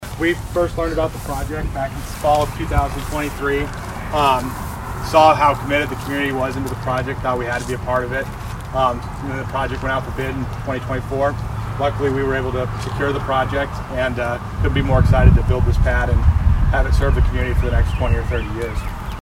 Groundbreaking ceremony held for the Atlantic SplashPad Project